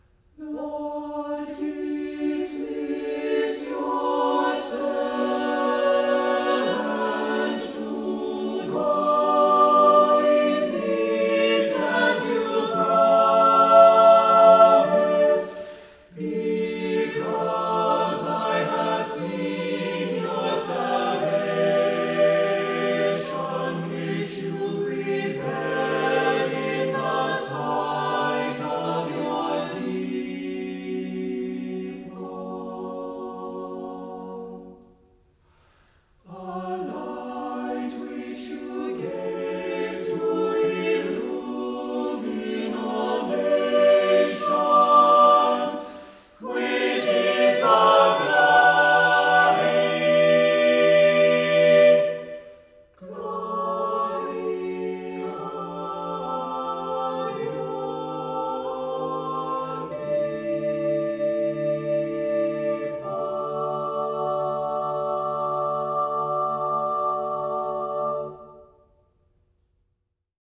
Voicing: a cappella,SSATB